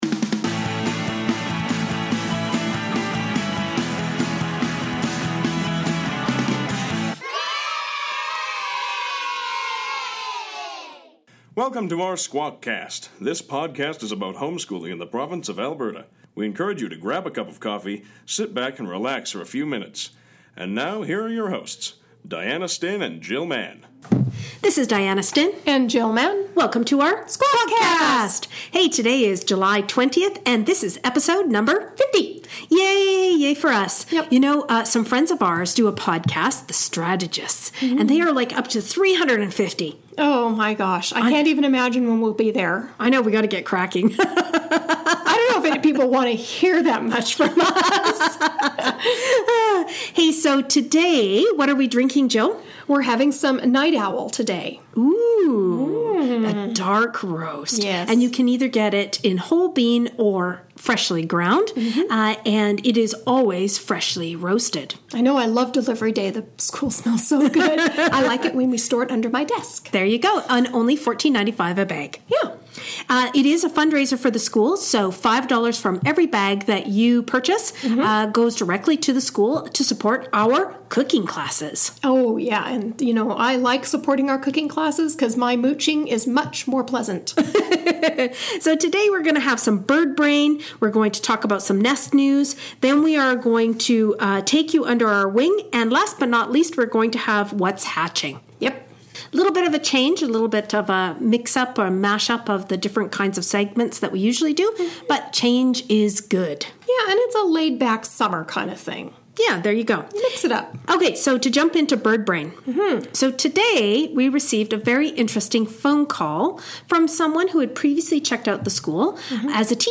Today we will chat about anxiety, basics, children, don’t believe the stereotype and evidence. WARNING: Lots of laughter!